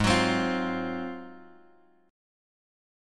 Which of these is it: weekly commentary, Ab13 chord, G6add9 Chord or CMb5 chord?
Ab13 chord